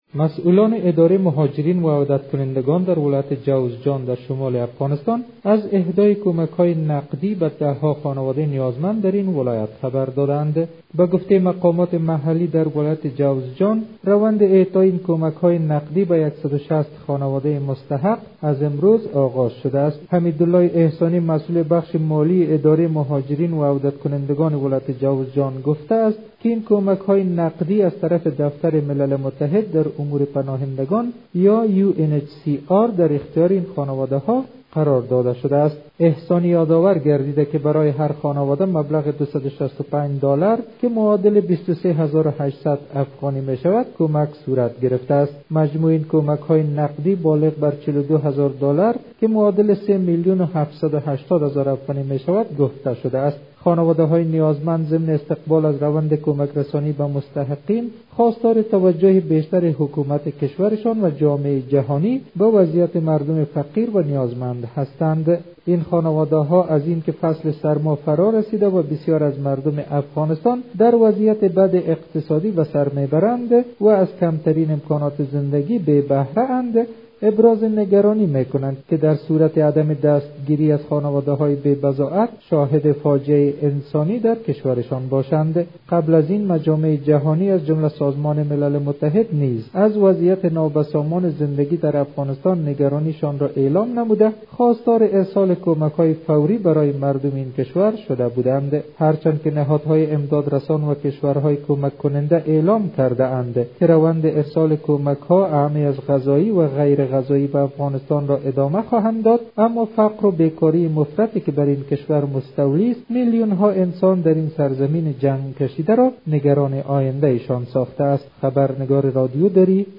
جزئیات بیشتر در گزارش تکمیلی